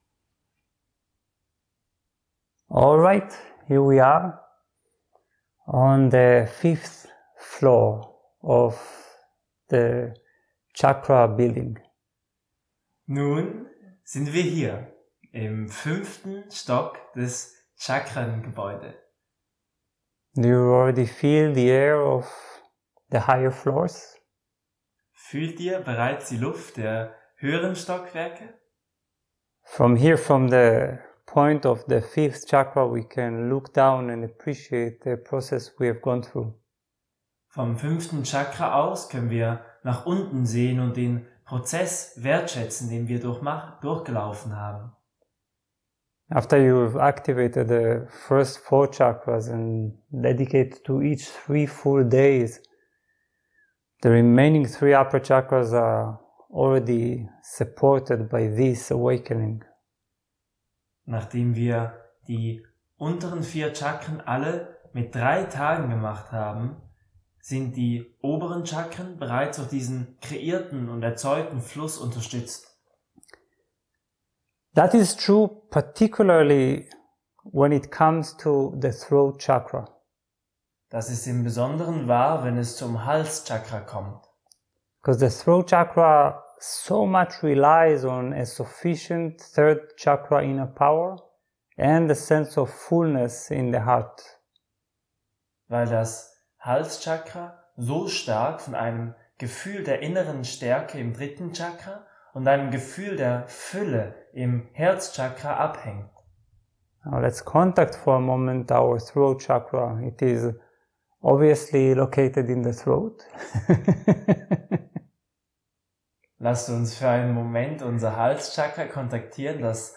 Meditation auf Deutsch